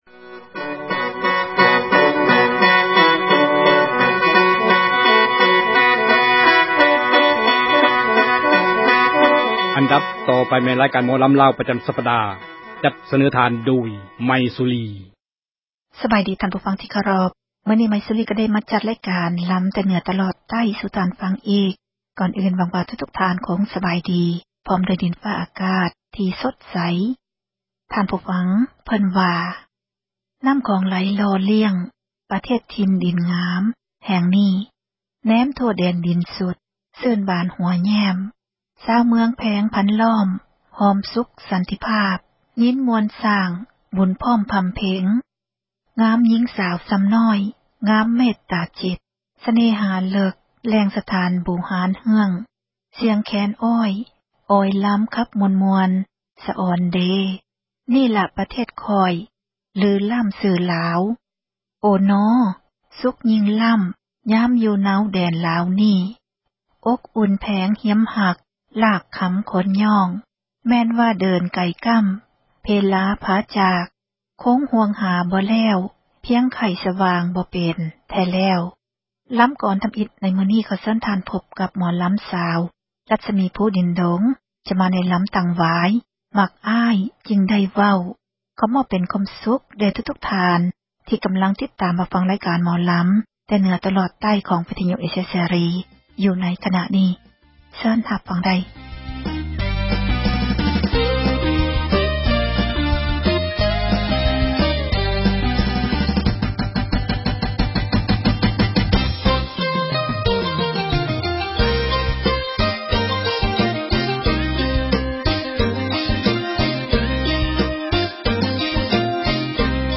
ຣາຍການໝໍລຳລາວ ປະຈຳສັປດາ.